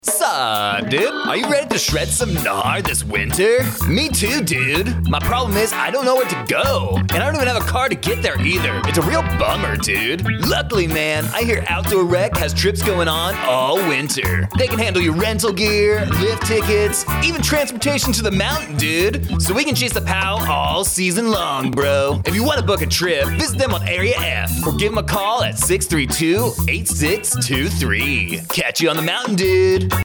A radio spot promoting Outdoor Recreation ski trips and winter sports equipment rental opportunities at Aviano Air Base.